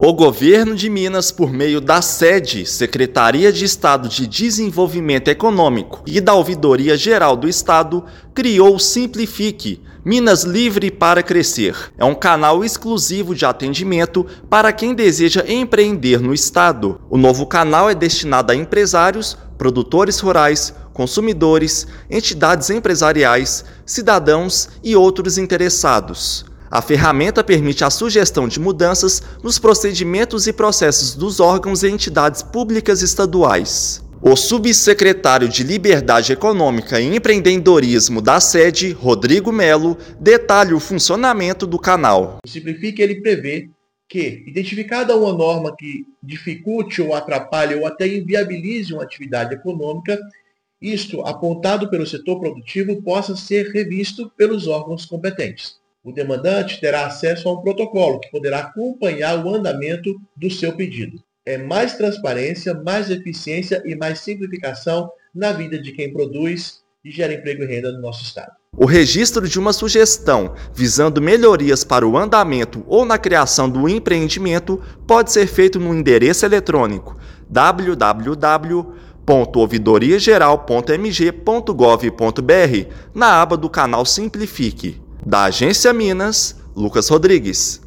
Ferramenta recebe sugestões para tornar serviços e processos públicos mais ágeis e menos burocráticos no Estado. Ouça matéria de rádio.